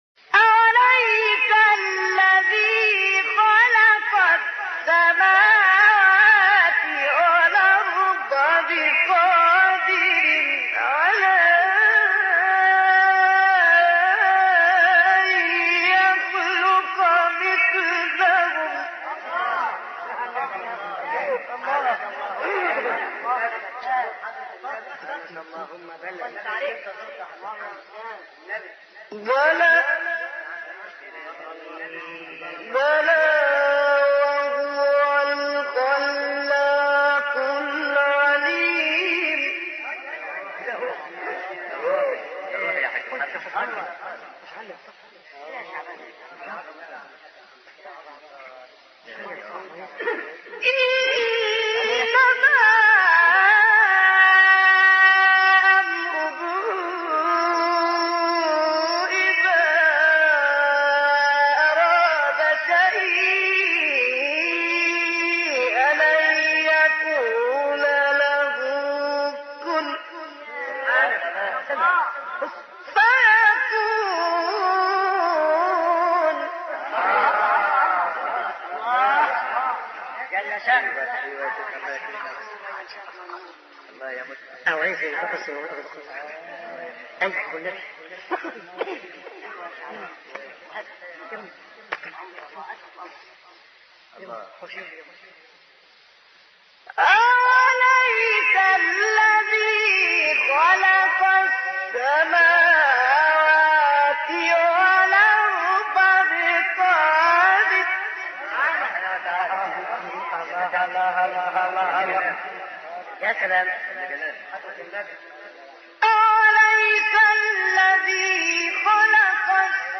مقام : بیات